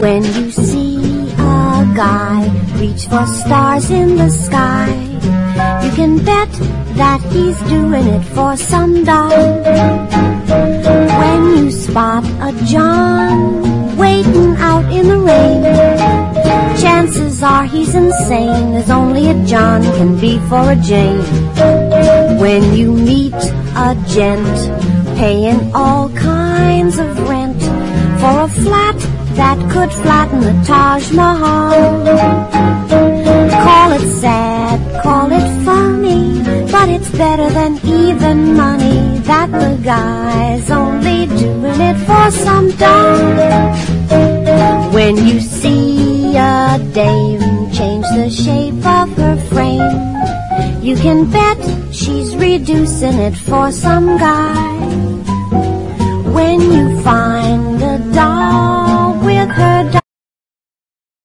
エレクトリック・ジャズ・ファンク/ジャズ・ロックな79年作！